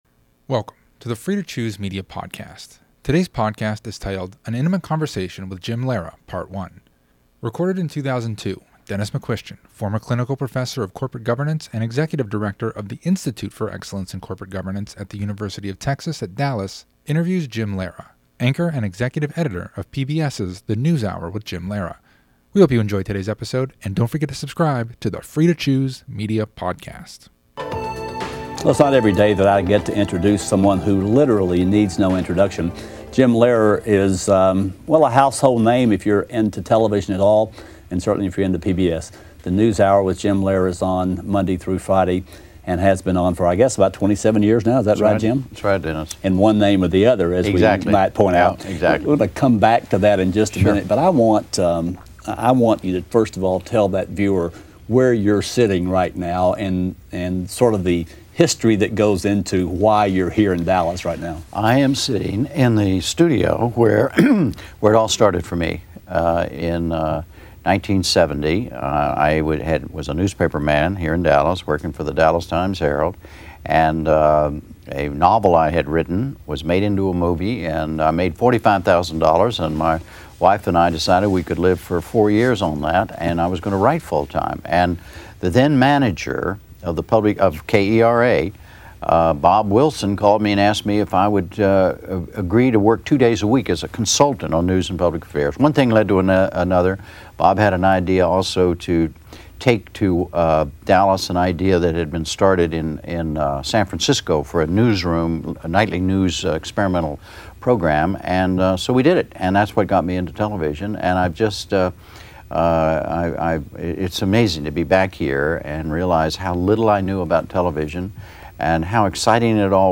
Episode 245 – An Intimate Conversation with Jim Lehrer, Part One